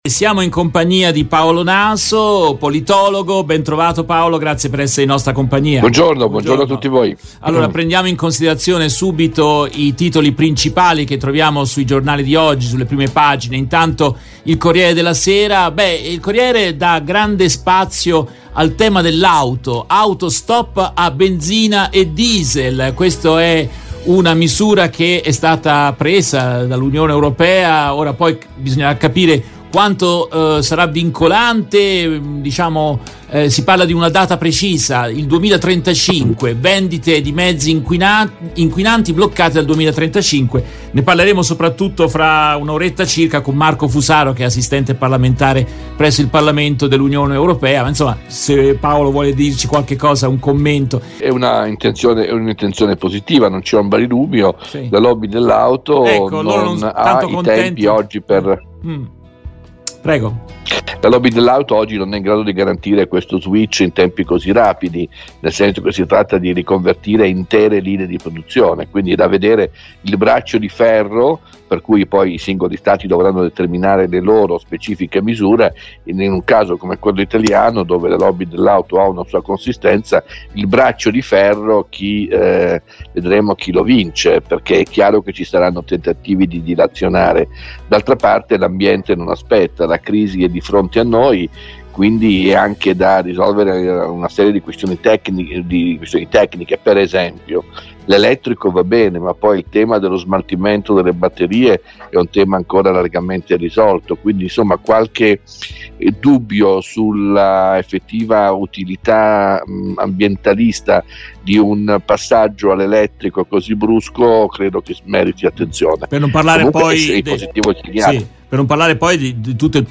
In questa intervista tratta dalla diretta RVS del 09 giugno 2022